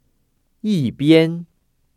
[yìbiān] 이삐엔  ▶